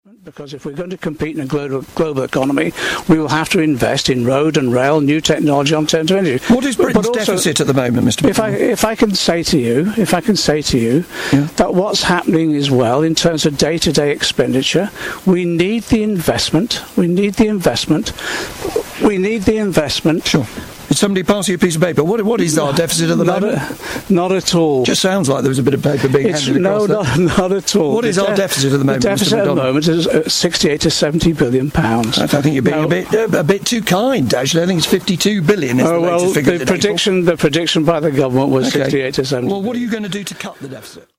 Shadow Chancellor John McDonnell today hesitated to spell out the current UK deficit figure and eventually got the number wrong during a BBC Radio 4 interview.